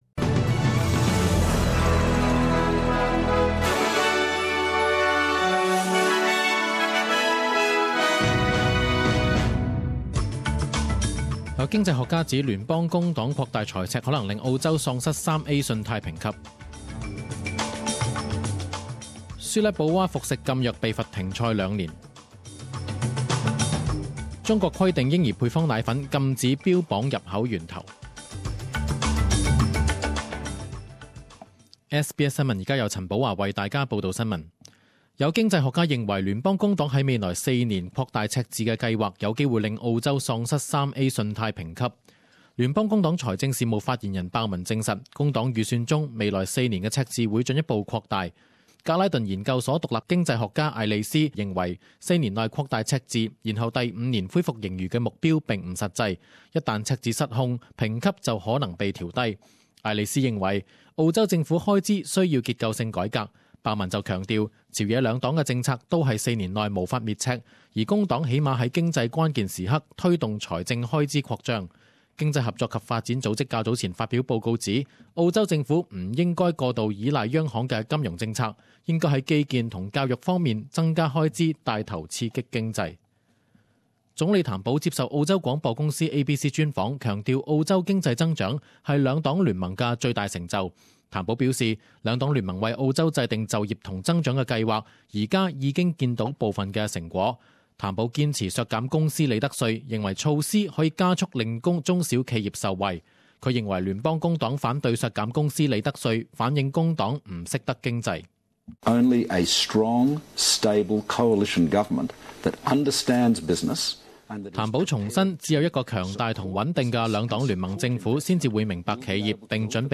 十点钟新闻报导 （六月十日）
10am News Bulletin 09.06.2016 Source: SBS